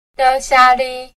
表記上は　1声―7声―2声　ですが、発音は　7声―7声―軽声　になります。